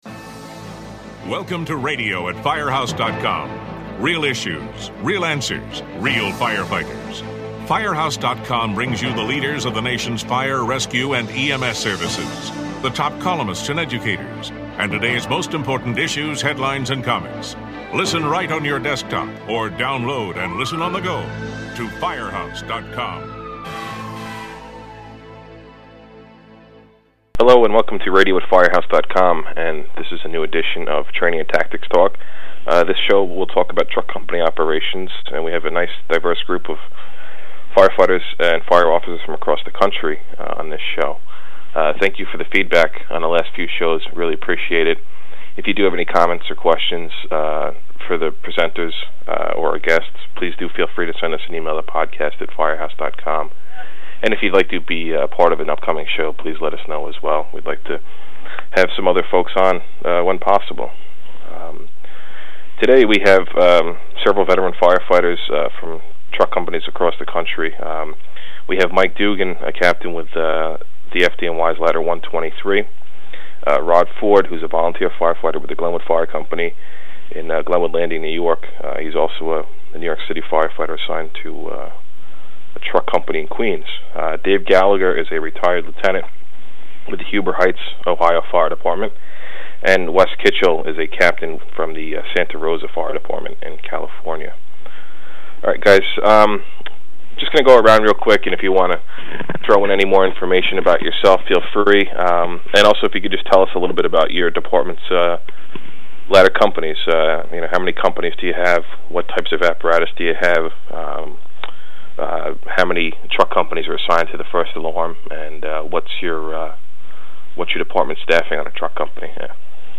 These veteran firefighters and officers will talk about their feelings on the issue.